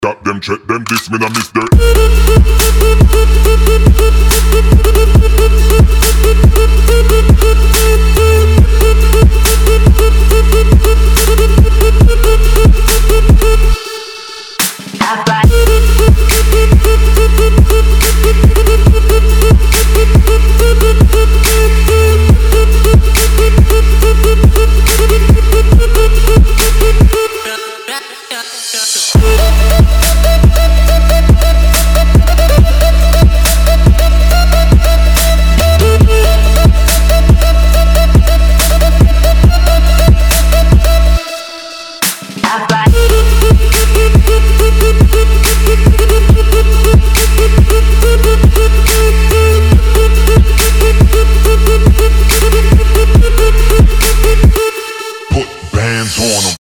• Качество: 320, Stereo
атмосферные
Electronic
EDM
Trap
Bass